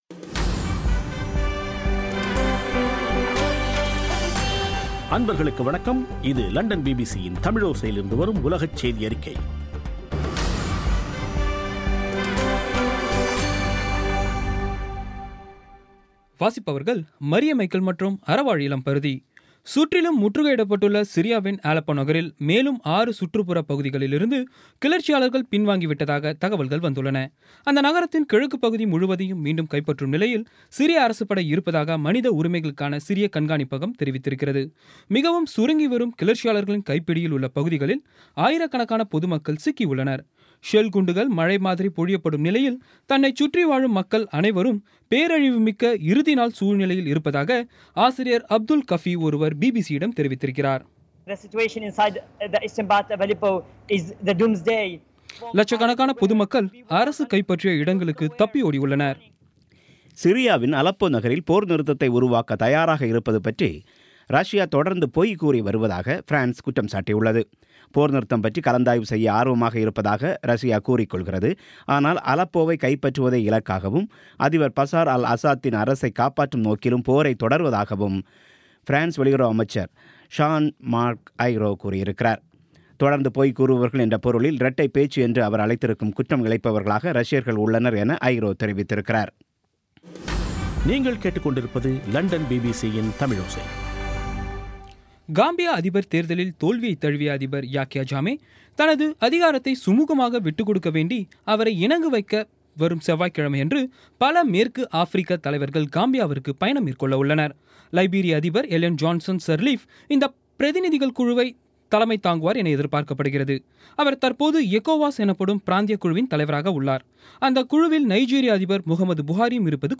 பி பி சி தமிழோசை செய்தியறிக்கை (12/12/16)